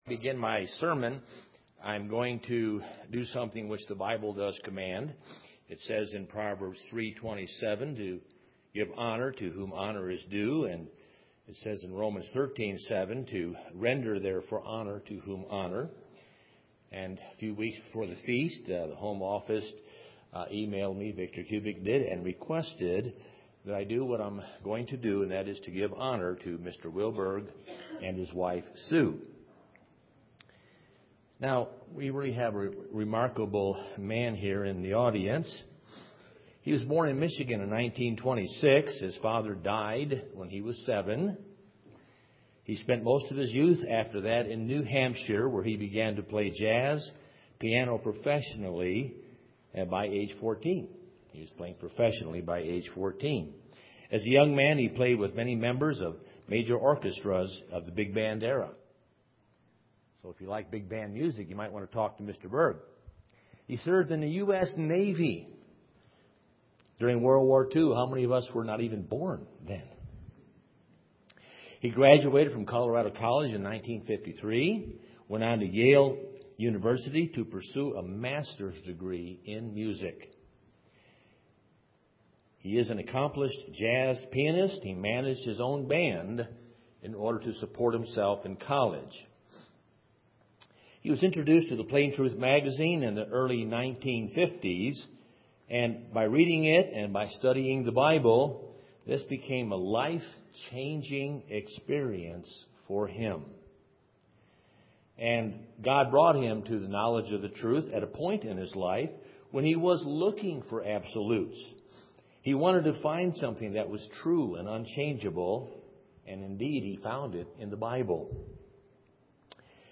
This sermon was given at the Steamboat Springs, Colorado 2012 Feast site.